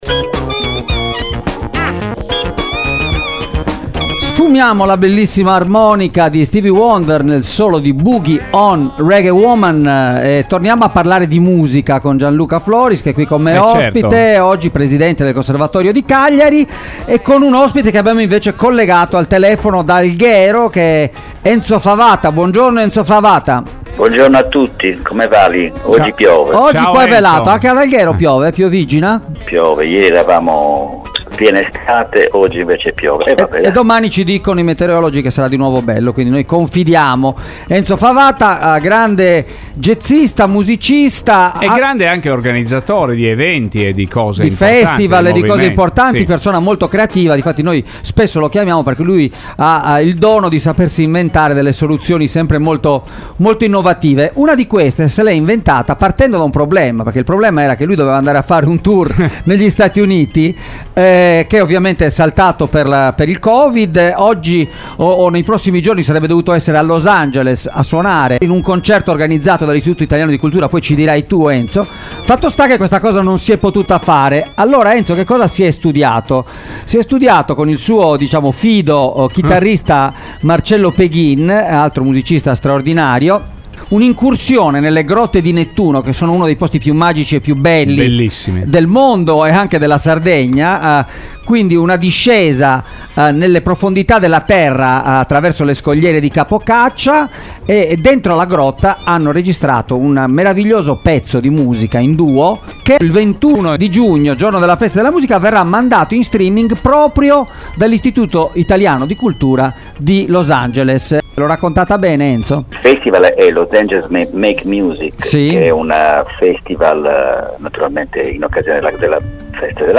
in collegamento da Alghero.